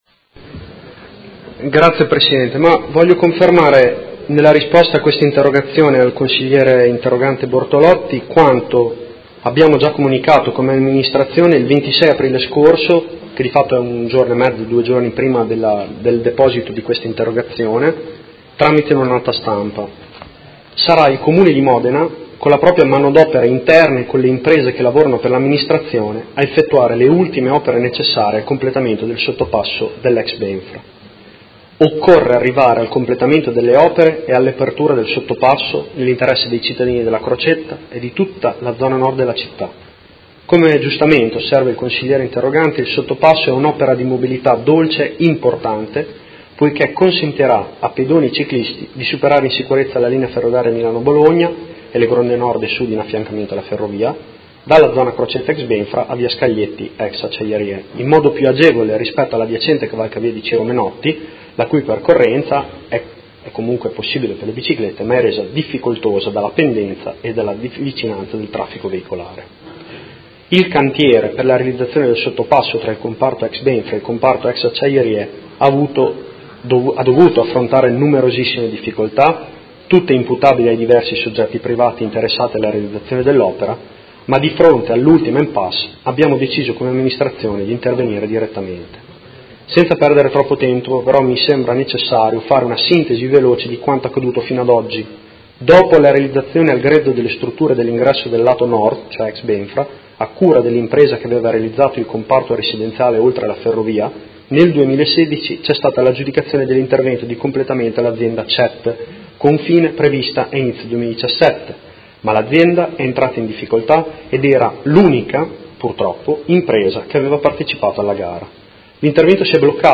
Seduta del 31/05/2018 Risponde. Interrogazione del Gruppo M5S avente per oggetto: Completamento sottopasso ferroviario tra zona Crocetta (ex BenFra) e Via Scaglietti (ex Acciaierie) a fianco del Cavalcavia Ciro Menotti